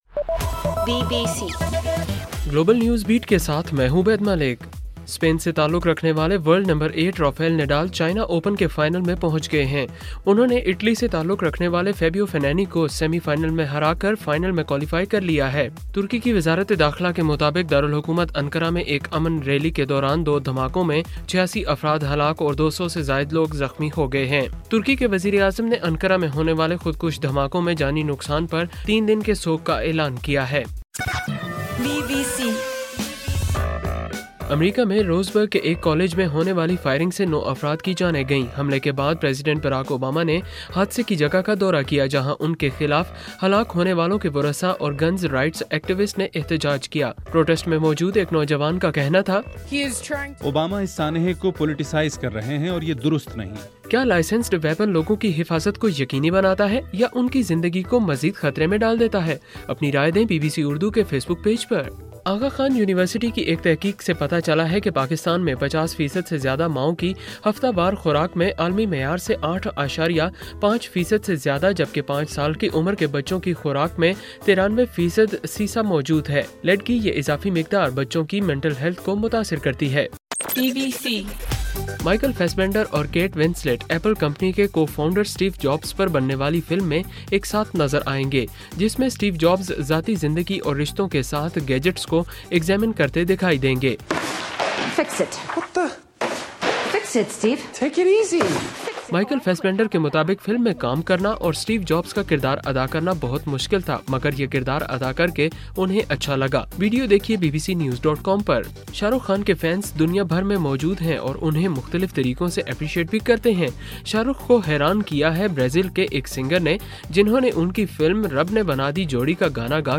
اکتوبر 11:صبح 1 بجے کا گلوبل نیوز بیٹ بُلیٹن